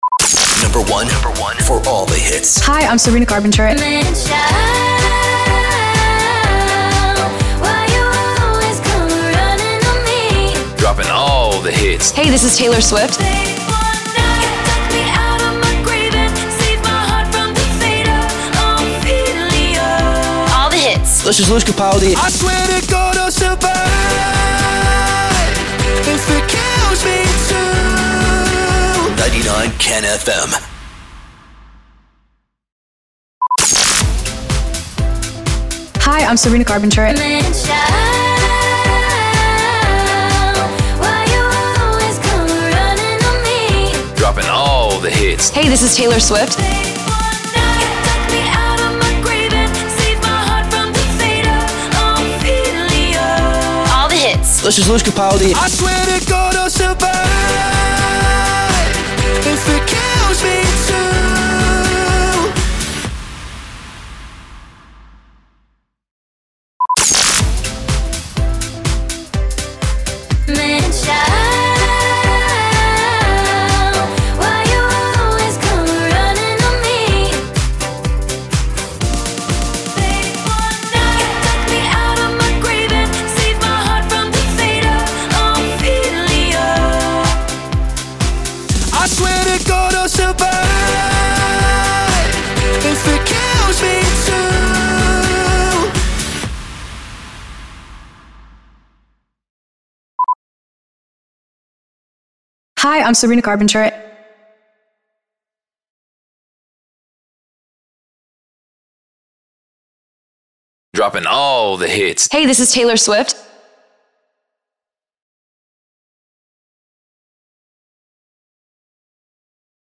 756 – SWEEPER – BEATMIX PROMO